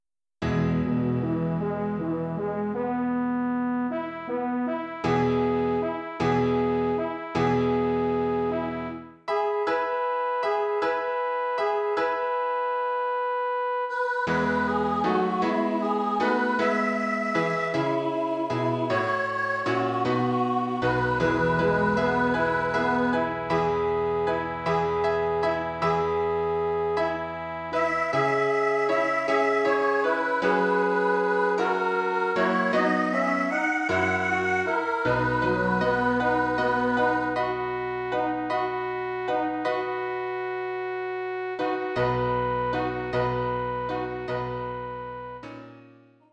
Horn und Klavier / Kammermusik / Originalwerke
Besetzung: Gesang, Horn, Klavier
Instrumentation voice, horn, piano